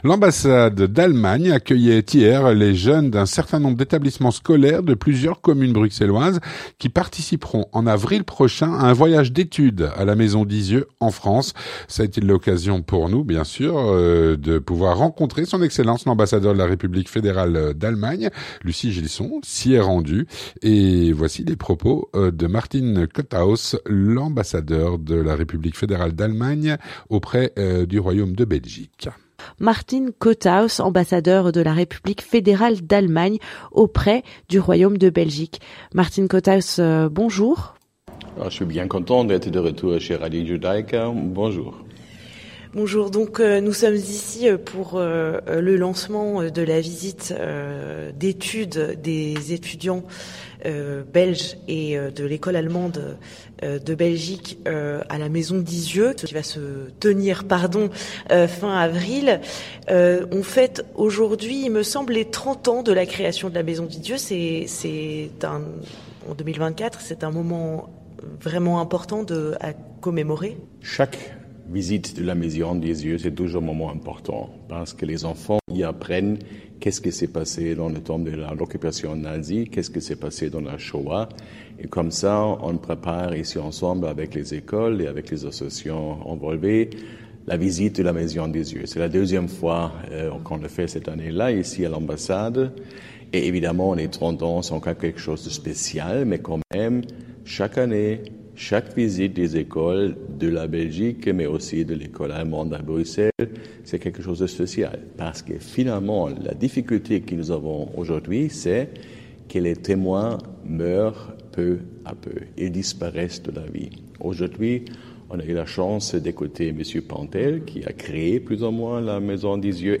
L'entretien du 18H - L'ambassade d'Allemagne accueillait des étudiants avant leur voyage d'étude à la Maison d'Izieu. Avec Martin Kotthaus (13/03/2024)
Avec S.E. Martin Kotthaus, ambassadeur d’Allemagne en Belgique